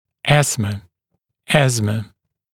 [‘æsmə ] [‘æzmə][‘эсмэ ] [‘эзмэ]астма, удушье